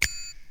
Southside Percussion (19).wav